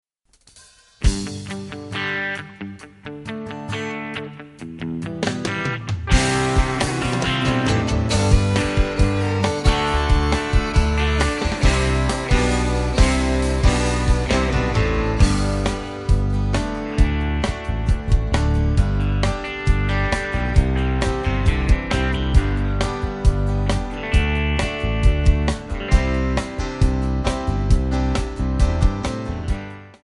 Backing track Karaokes